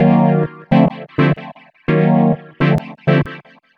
Chocapads Bb 127.wav